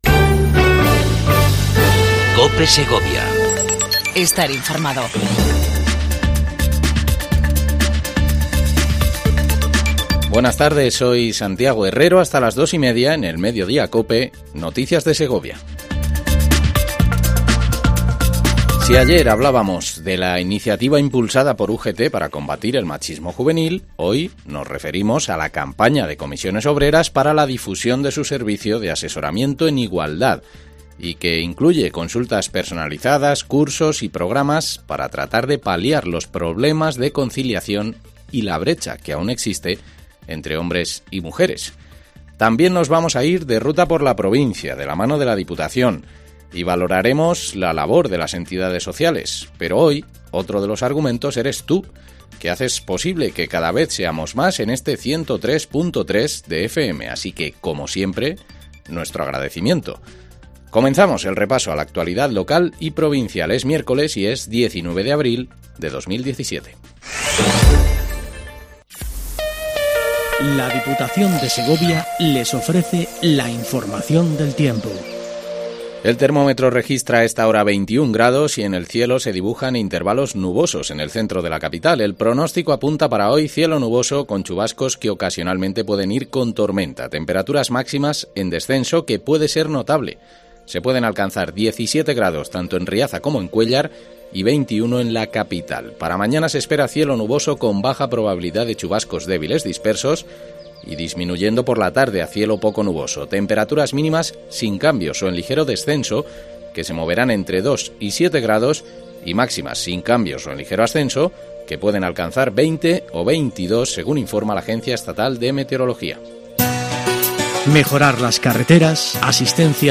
INFORMATIVO MEDIODIA COPE EN SEGOVIA 19 04 17